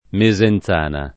[ me @ en Z# na ]